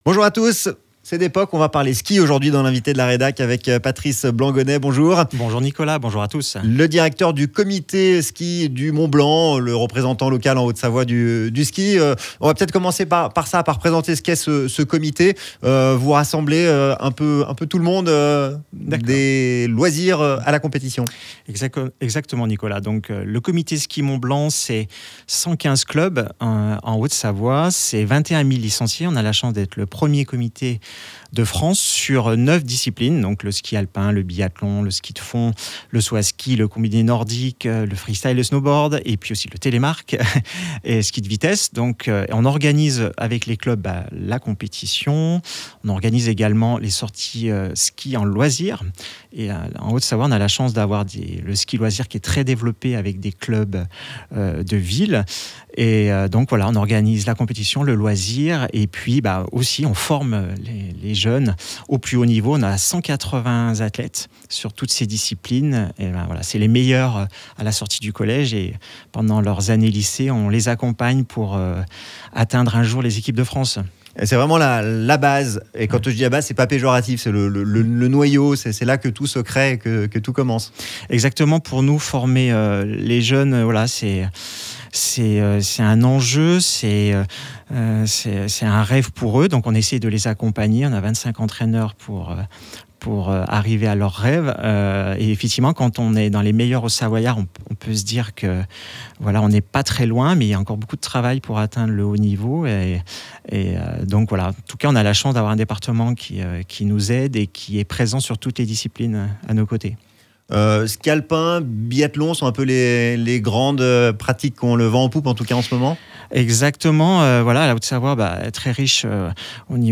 Il était l’Invité de la Rédac sur ODS Radio : Télécharger le podcast Partager : Tags : ski haute-savoie mont-blanc comite-ski-du-mont-blanc